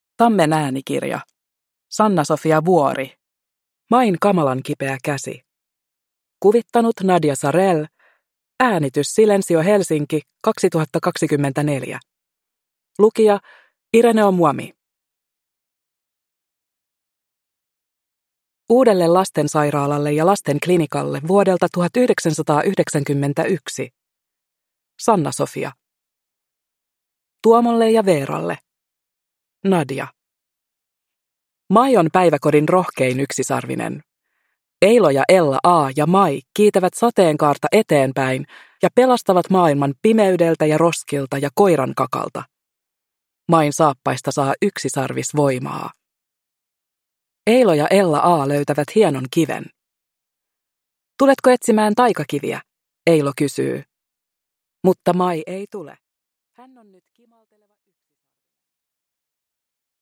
Main kamalan kipeä käsi – Ljudbok